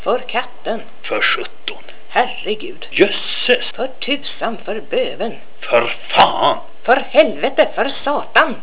Для прослушивания или скачивания звукового файла, содержащего произношение приведенных примеров, пожалуйста, нажмите на название соответствующего раздела.